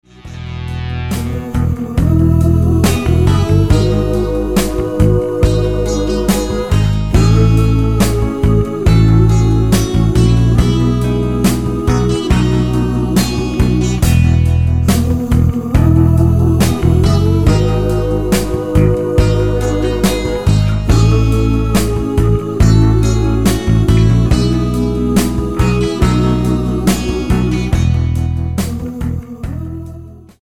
Tonart:E mit Chor